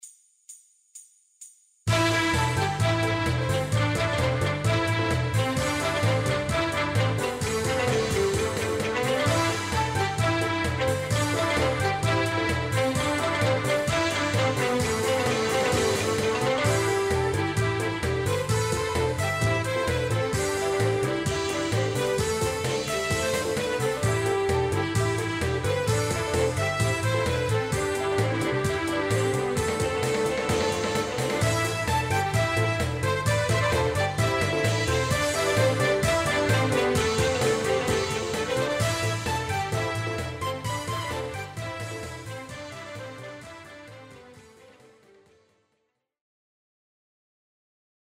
BGM
アップテンポショート